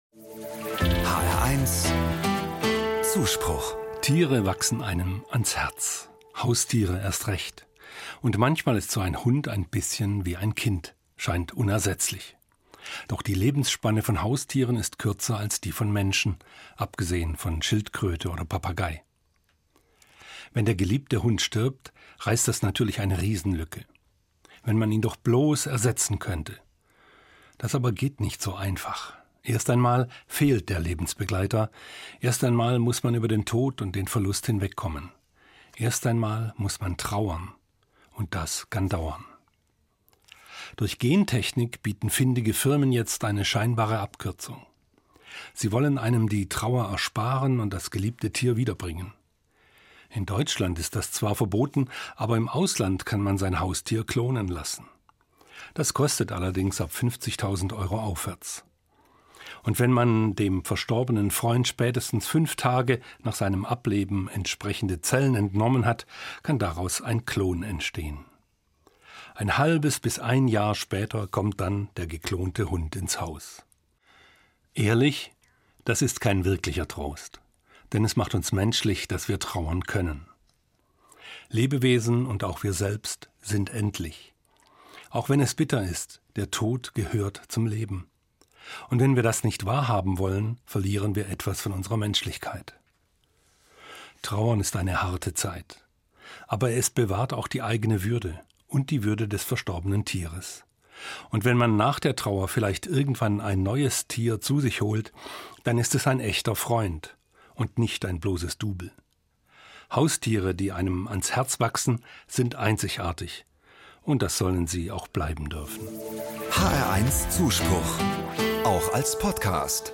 Evangelischer Pfarrer, Frankfurt